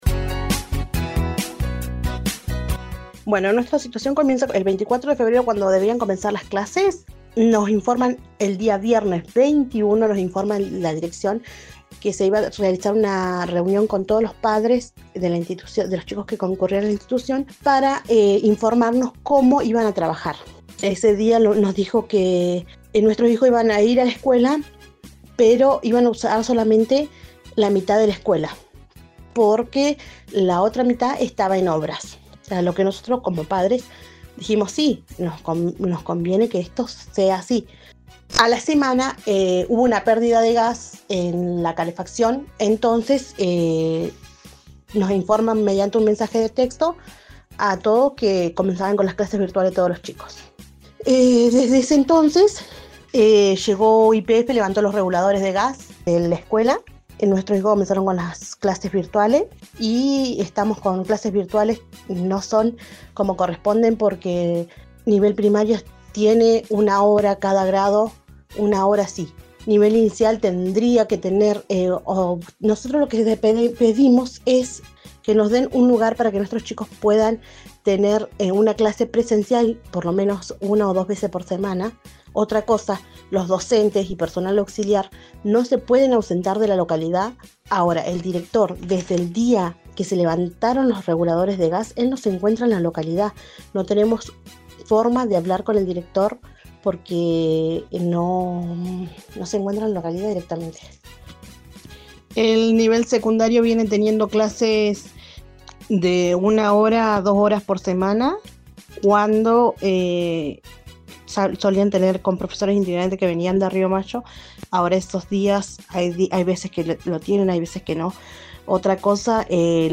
Así lo explicaba una mamá en el aire de RADIOVISIÓN: